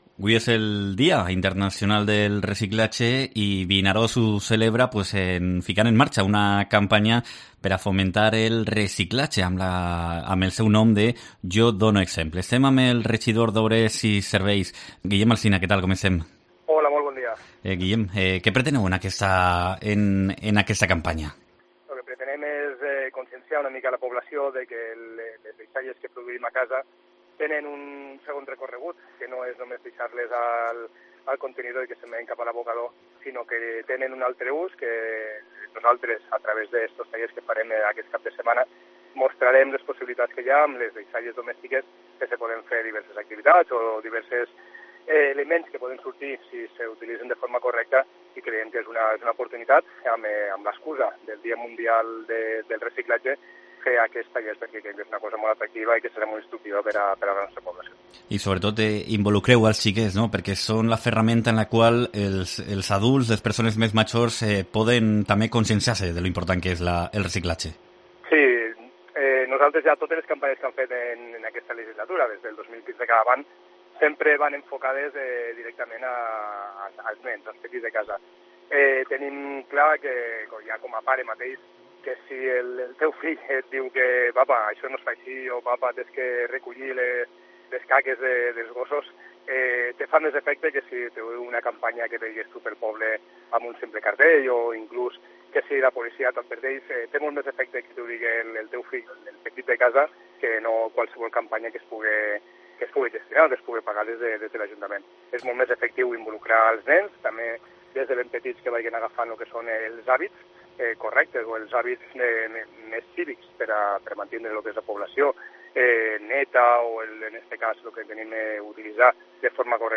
Entrevista a Guillem Alsina (ajuntament de Vinaròs)